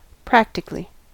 practically: Wikimedia Commons US English Pronunciations
En-us-practically.WAV